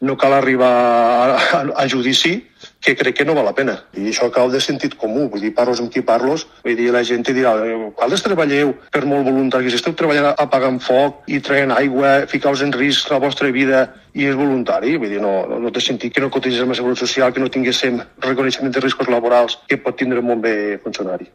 [ENTREVISTA] Els bombers voluntaris alcen la veu: «no és voluntariat, estem treballant com a bombers»